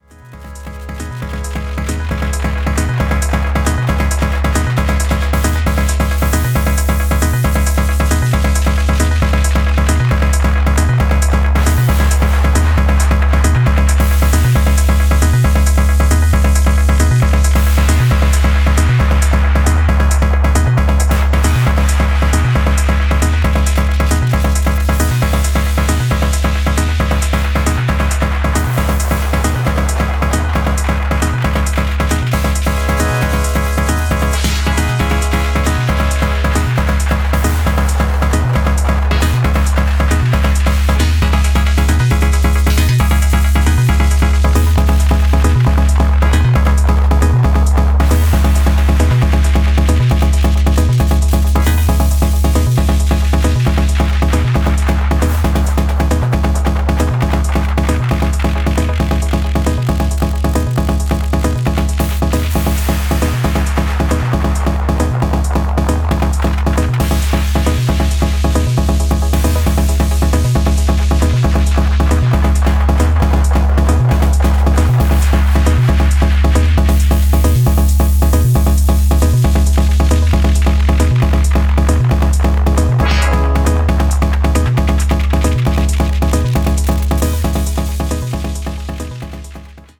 キックを用いずにクラウドのテンションをピークに誘う強烈チューン